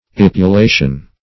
Search Result for " epulation" : The Collaborative International Dictionary of English v.0.48: Epulation \Ep`u*la"tion\, n. [L. epulatio.]